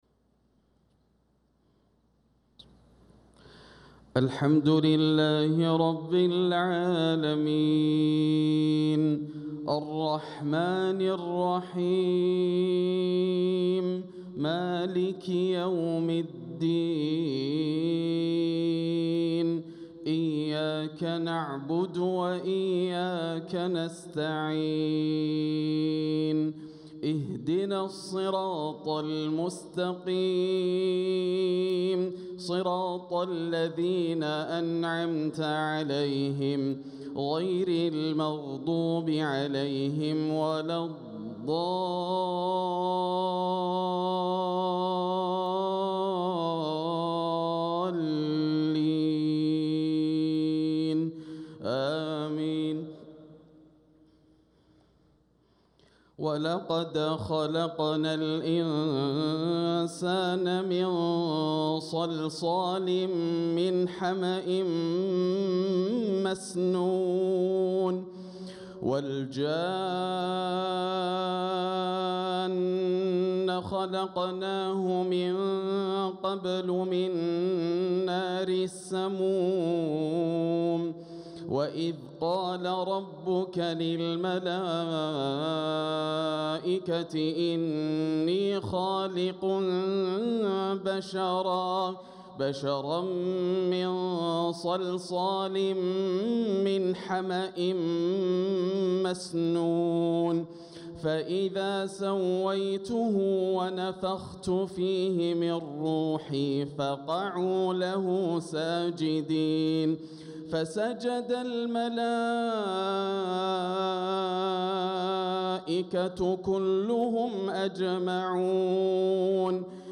صلاة العشاء للقارئ ياسر الدوسري 4 ربيع الأول 1446 هـ
تِلَاوَات الْحَرَمَيْن .